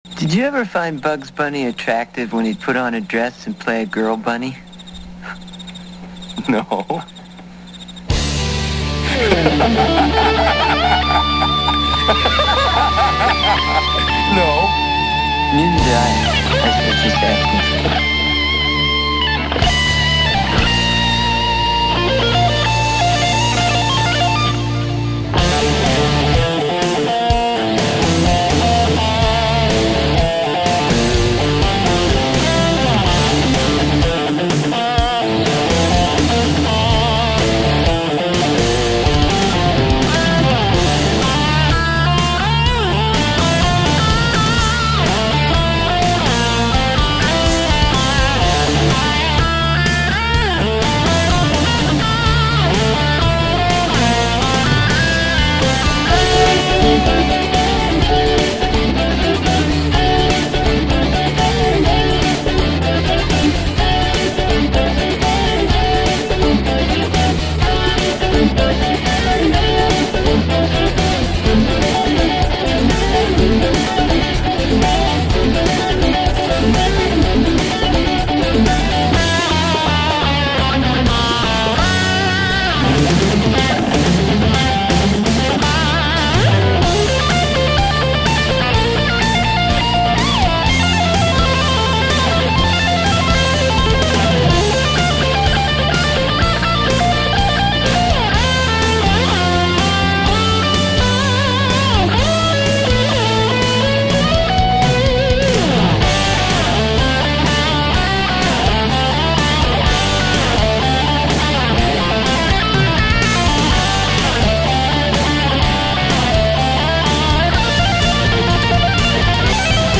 [Rock]